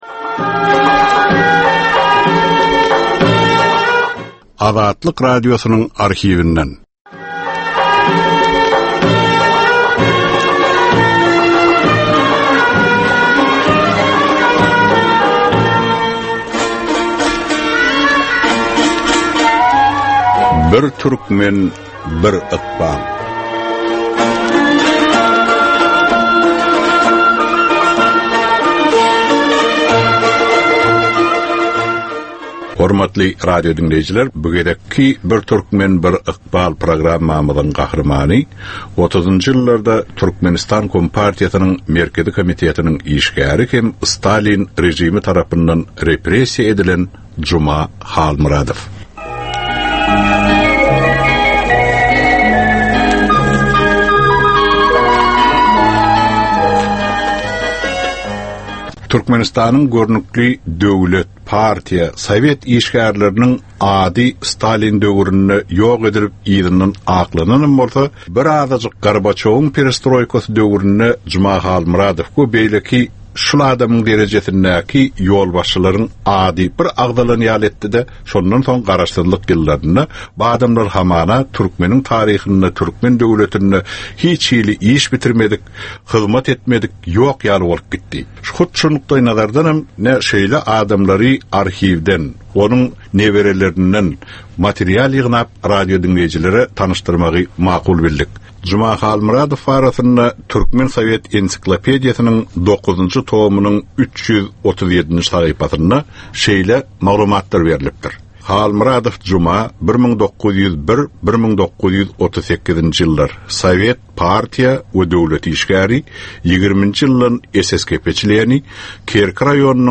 Türkmenistan we türkmen halky bilen ykbaly baglanyşykly görnükli şahsyýetleriň ömri we işi barada 55 minutlyk ýörite gepleşik. Bu gepleşikde gürrüňi edilýän gahrymanyň ömri we işi barada giňişleýin arhiw materiallary, dürli kärdäki adamlaryň, synçylaryň, bilermenleriň pikirleri, ýatlamalary we maglumatlary berilýär.